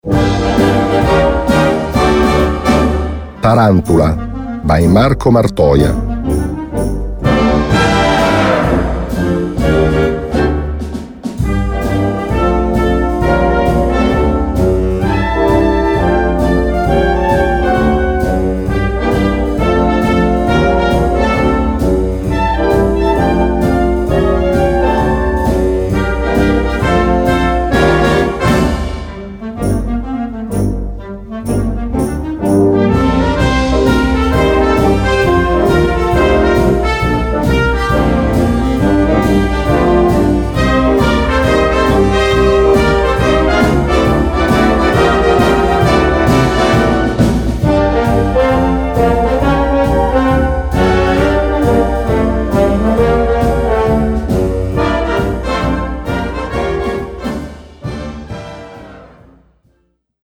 Bladmuziek voor harmonie.